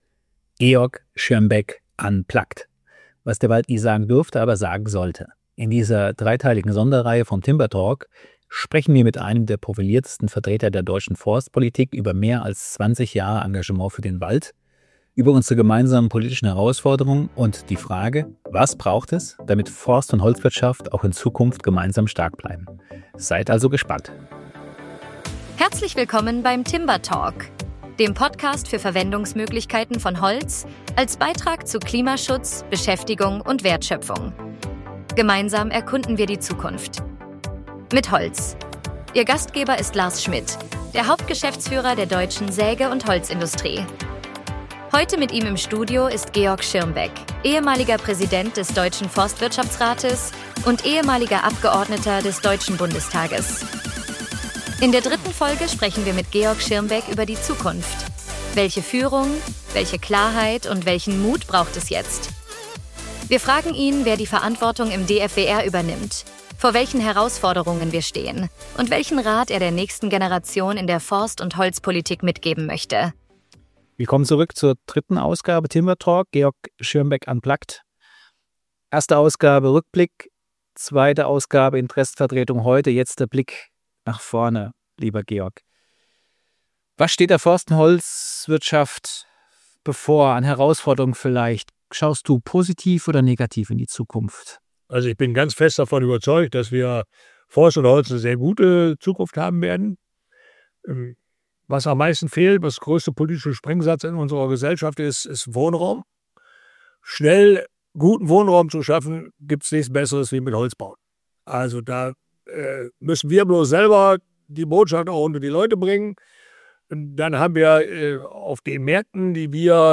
In der dritten Folge sprechen wir mit Georg Schirmbeck über die Zukunft: Welche Führung, welche Klarheit und welchen Mut braucht es jetzt? Wir fragen ihn, wer die Verantwortung im DFWR übernimmt, vor welchen Herausforderungen wir stehen - und welchen Rat er der nächsten Generation in der Forst- und Holzpolitik mitgeben möchte.